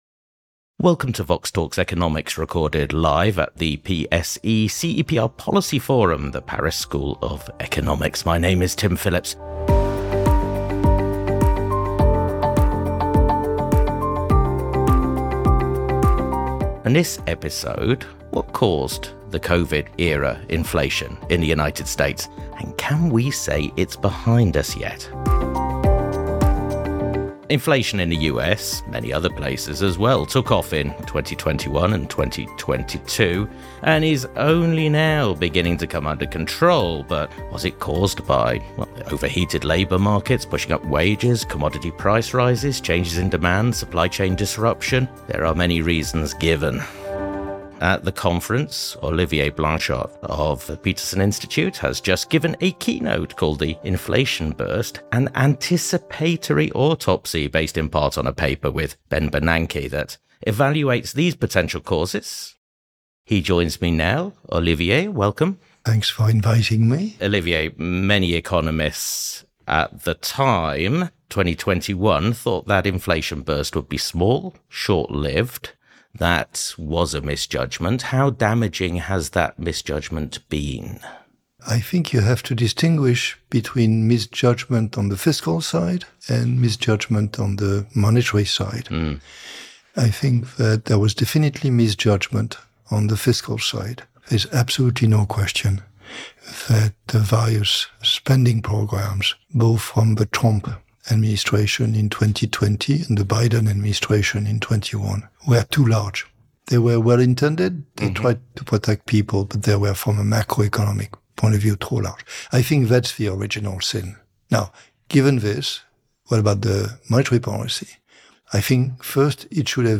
From the PSE-CEPR Policy Forum at the Paris School of Economics. What caused inflation in the US, where will it settle, and how much unemployment will be the cost of bringing it back to target?